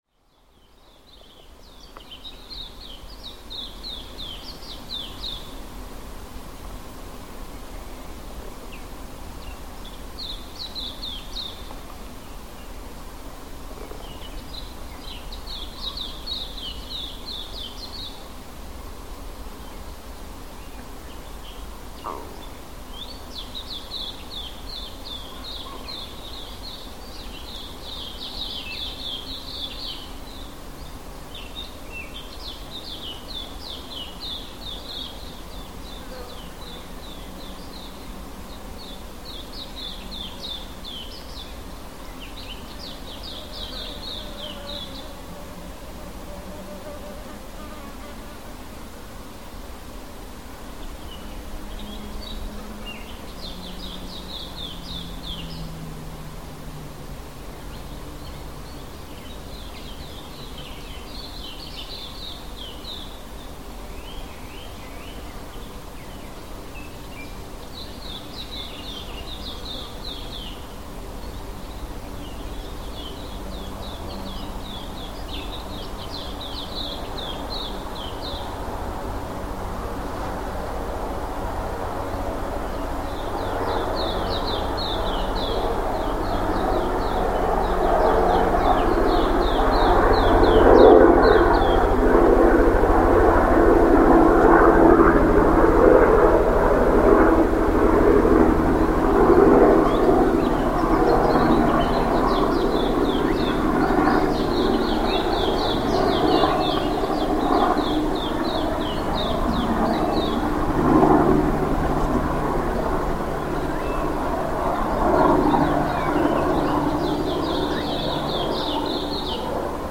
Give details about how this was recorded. Field recording from Cape Town, South Africa.